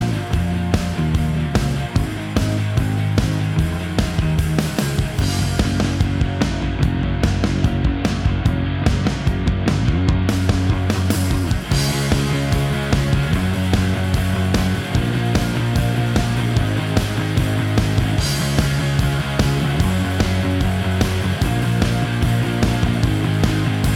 No Lead Guitar Pop (2010s) 3:41 Buy £1.50